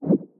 now-playing-pop-out.wav